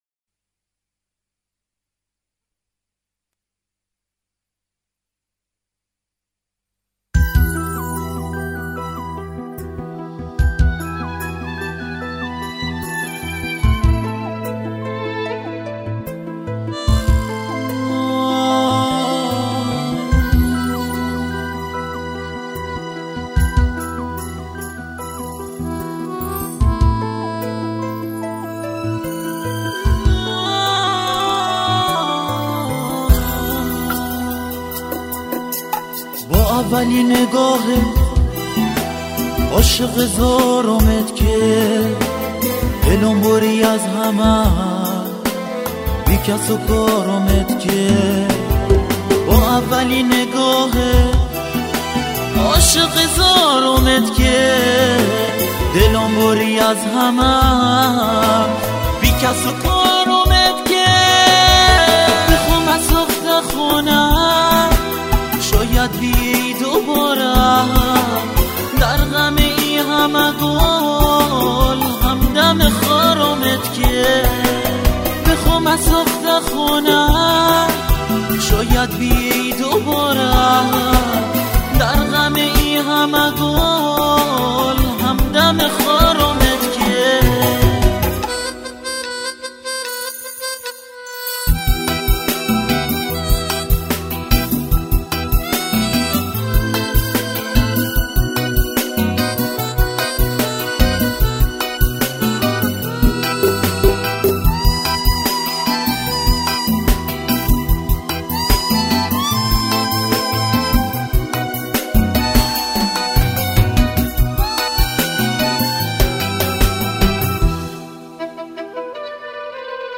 تمام تراک‌های این آلبوم به سبک و گویش بندری است.